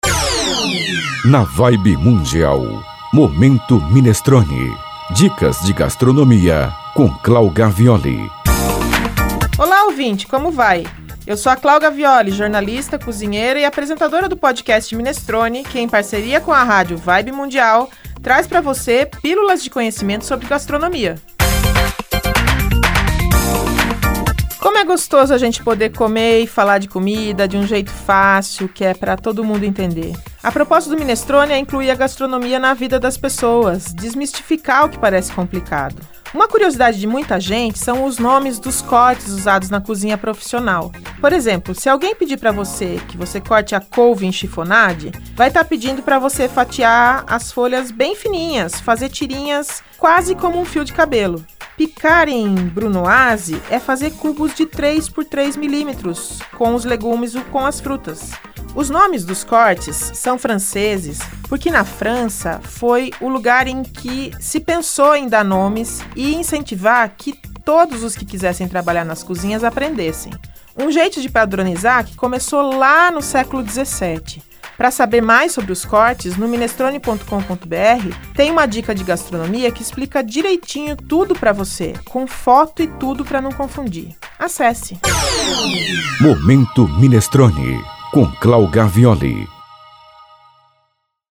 Três vezes ao dia, durante a programação da rádio (às 7h25, 14h25 e 22h25), são veiculadas pílulas de conhecimento em gastronomia.